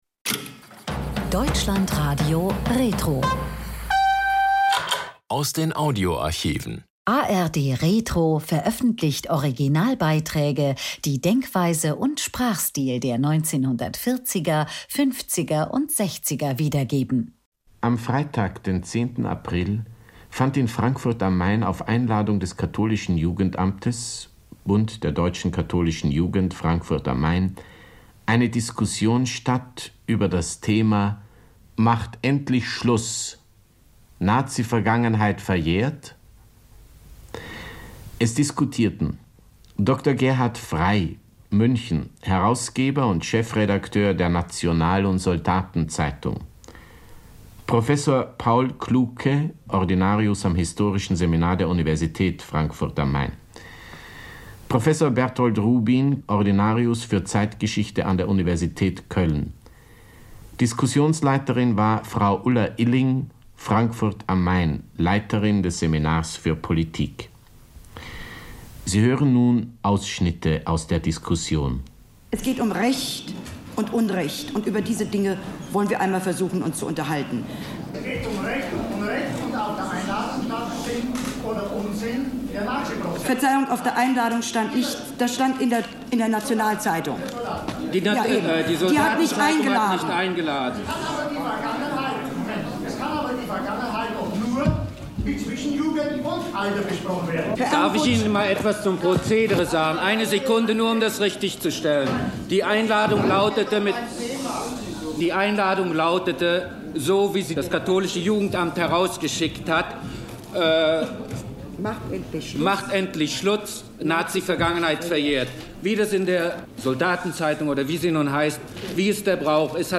Der Deutschlandfunk sendet anlässlich des ersten Auschwitz-Prozesses Teile einer hitzigen Diskussion mit kontroversen Meinungen zur Aufarbeitung der Nazi-Verbrechen